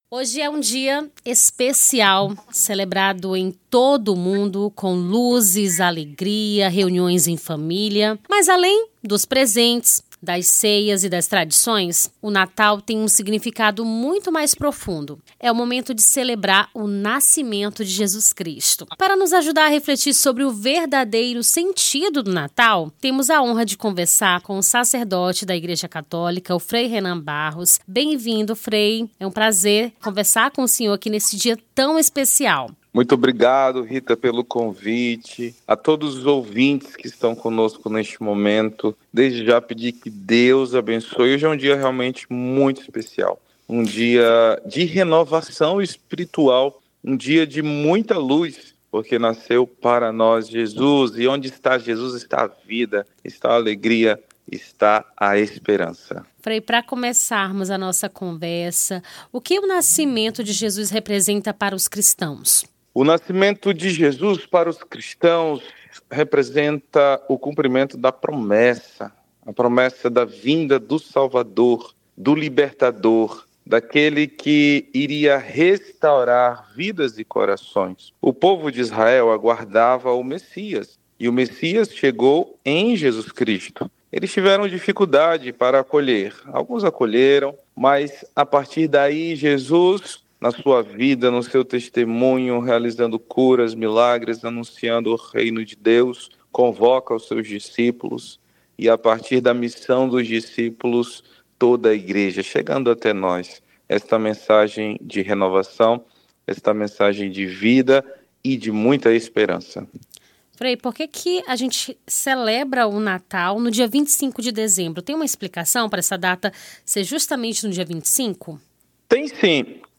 AO VIVO: Confira a Programação
Nome do Artista - CENSURA - ENTREVISTA SIGNIFICADO DO NATAL (25-12-24).mp3